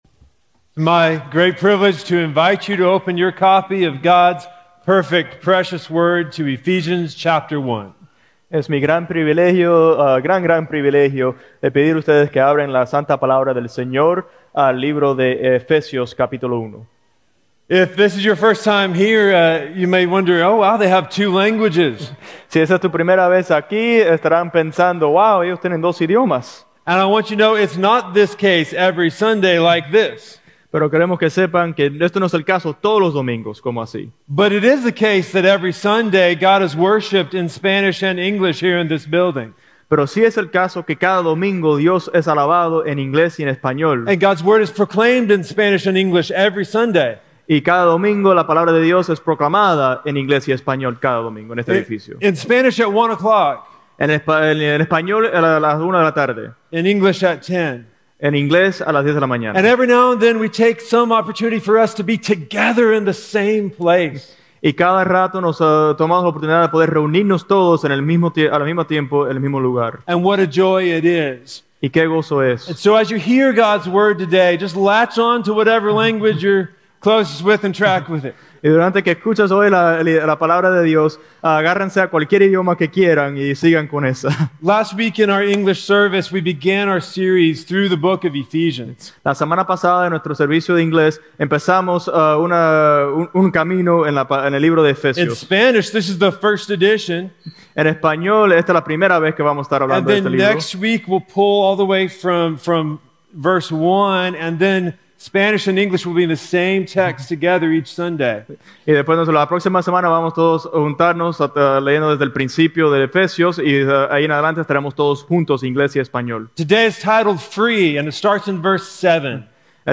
Free (Ephesians 1:7-10) with Spanish Translation | Ashland Podcast
Sermon Audio